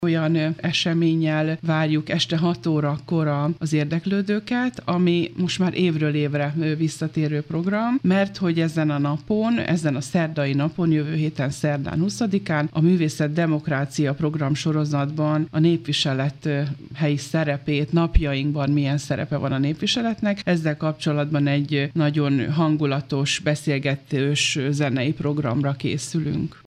A Trafik Kör ezúttal a népviselet és a hagyomány modern korba történő átültetését járja körül a szegedi papucs segítségével. Pálinkásné Balázs Tünde alpolgármestert hallják.